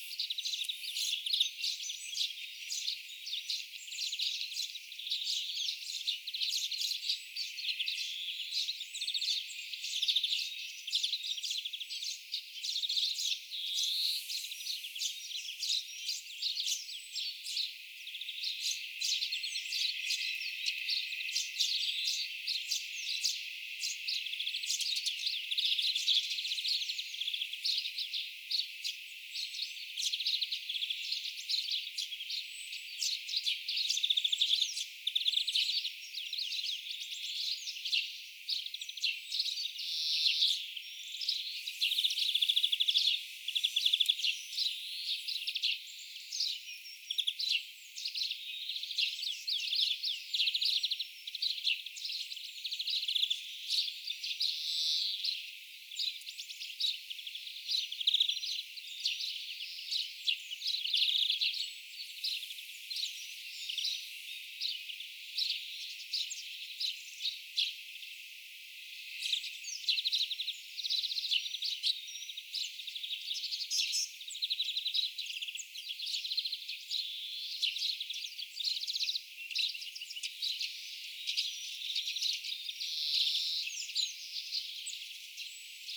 tassa ilmeisesti laulaa pikkuvarpunen
vähän varpusmaisesti ja
vähän myöskin varpunen
tassa_ilmeisesti_laulaa_pikkuvarpunen_varpusmaisesti_ja_myoskin_aito_varpunen_hiukan_olenko_oikeassa.mp3